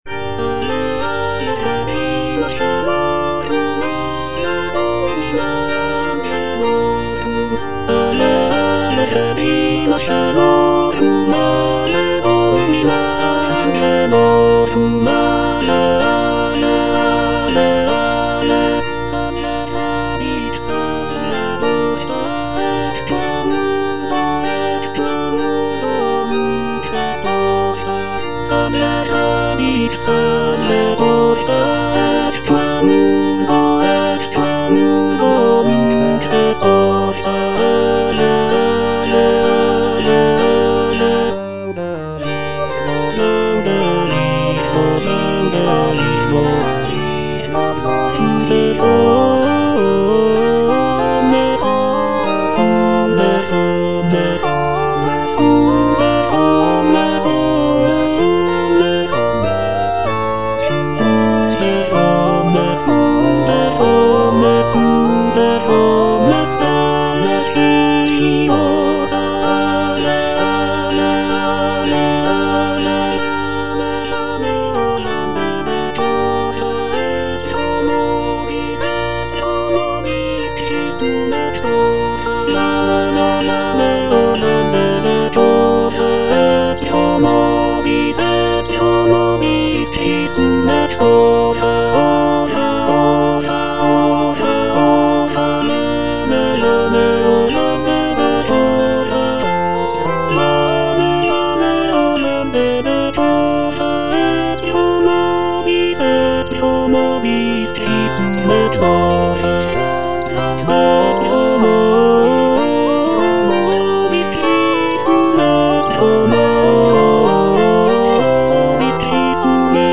I. LEONARDA - AVE REGINA CAELORUM Tenor (Emphasised voice and other voices) Ads stop: auto-stop
"Ave Regina caelorum" is a sacred vocal work composed by Isabella Leonarda, a 17th-century Italian composer and nun. The piece is a hymn dedicated to the Virgin Mary, often sung during the season of Lent.